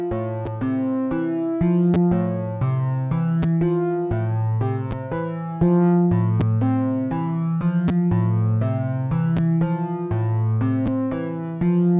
crack and pops with Animoog z